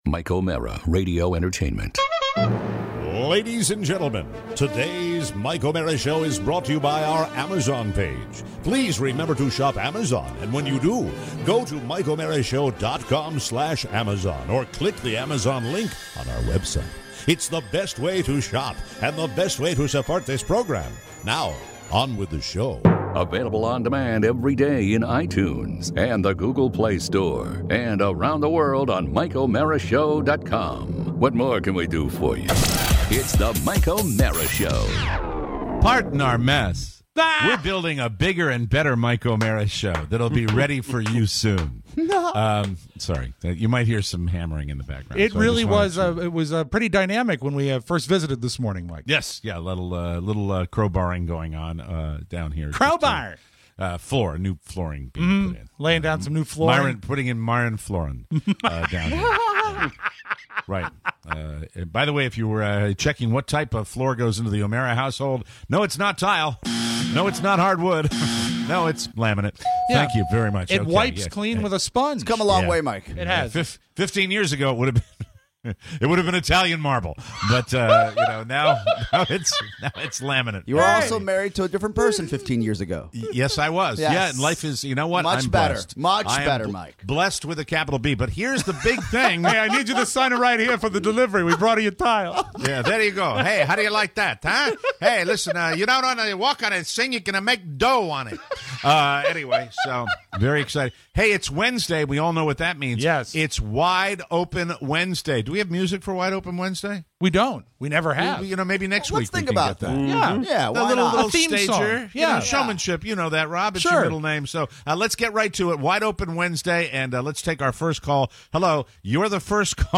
It’s a Wide Open Wednesday and we take your calls!